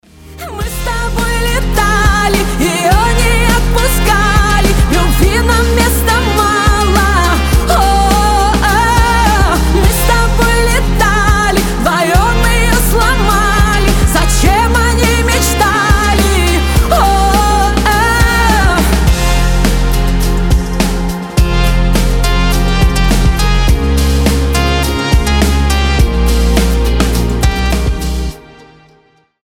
поп , женский голос